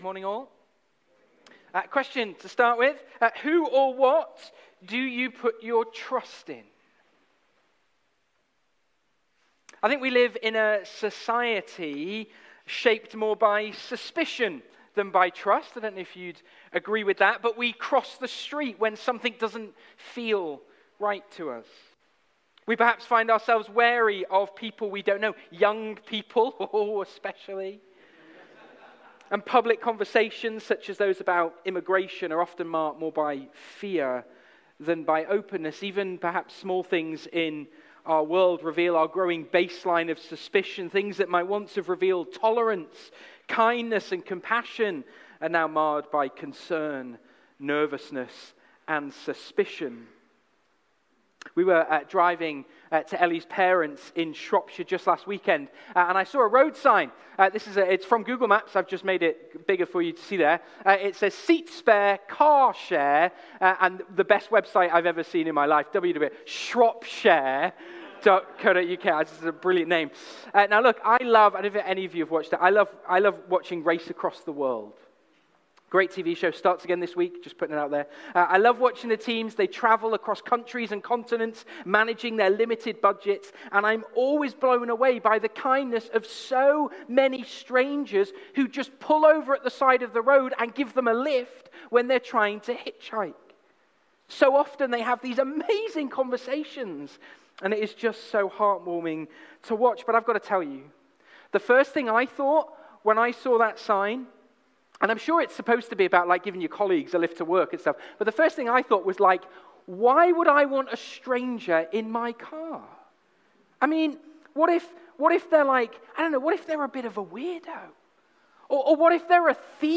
Passage: Luke 23:44-49, Psalm 31:1-5 Service Type: Sunday Morning